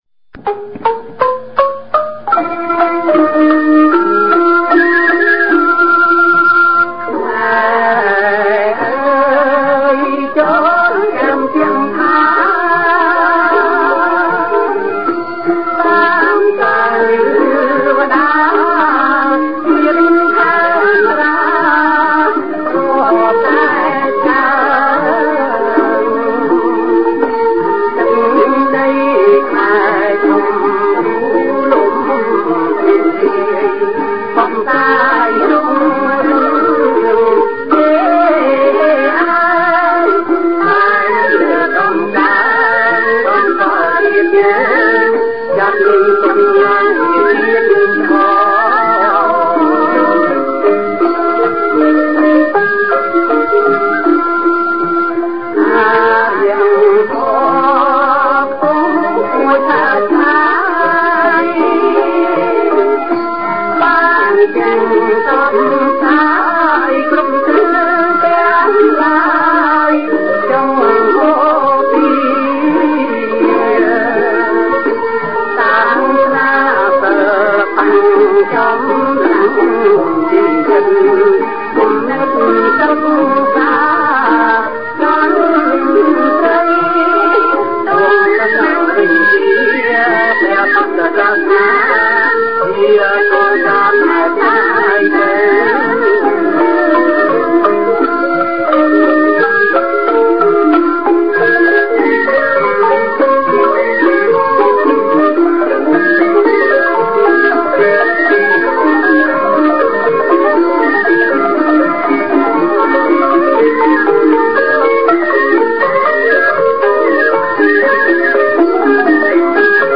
videoplayback_G_major__bpm_76